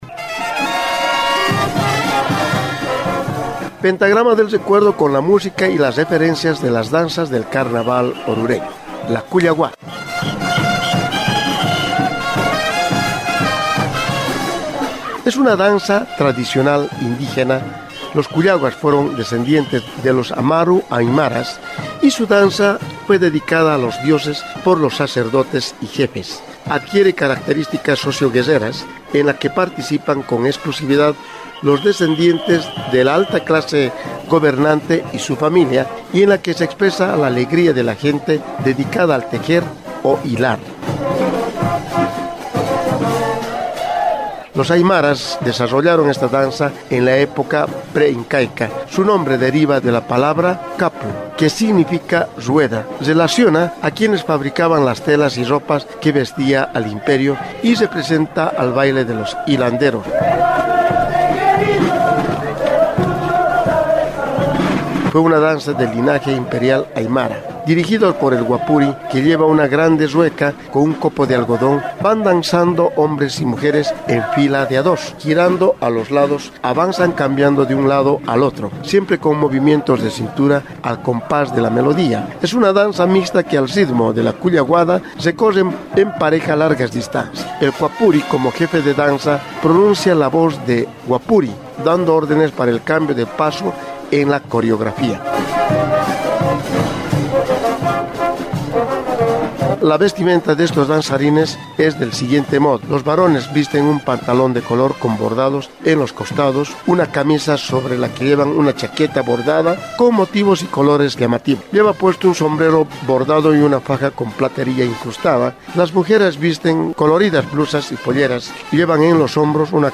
Carnaval de Oruro
Originalmente, la danza se ejecutaba con instrumentos de viento como quenas y flautas, pero en la actualidad son las bandas de músicos las que las acompañan.